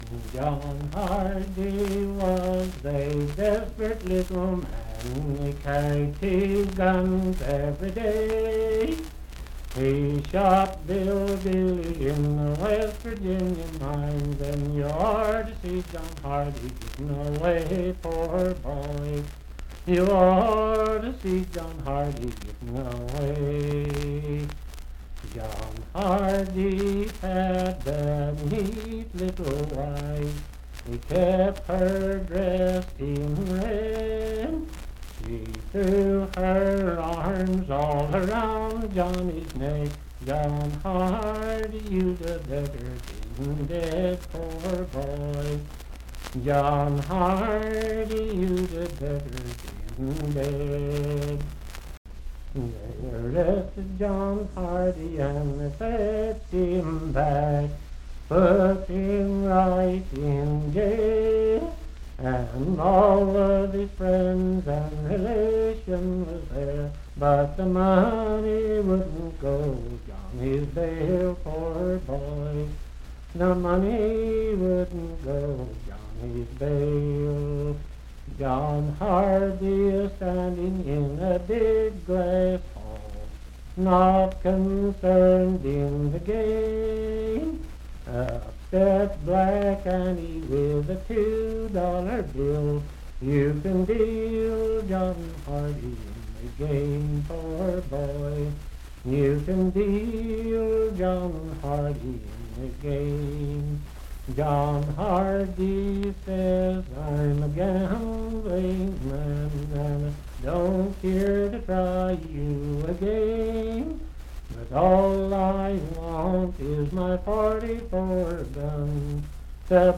Unaccompanied vocal music
in Dryfork, WV
Voice (sung)